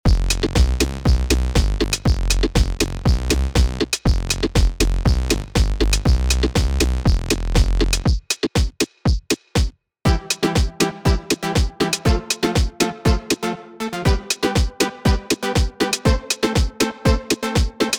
Quick Demo of the presets:
Preset Dance Pluck:
Preset Bassline Madness: